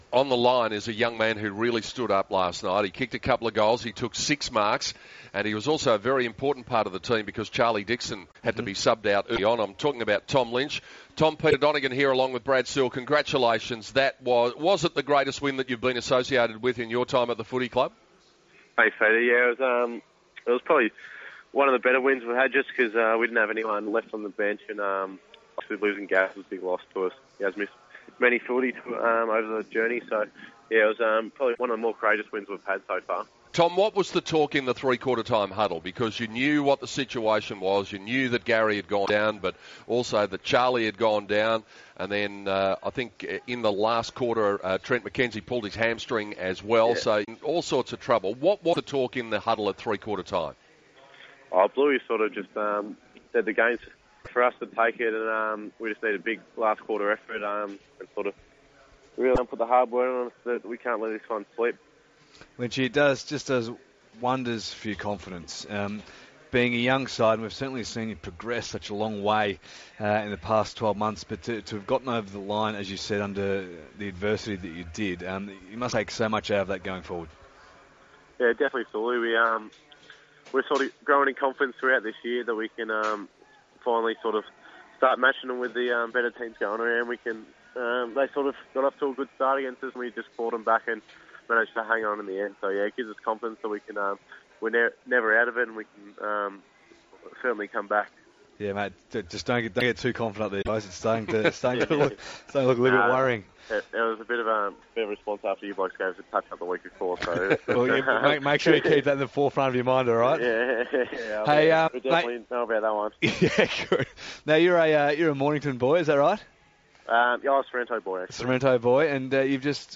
Tom Lynch speaks with AFL Live Radio after the SUNS win over the Pies.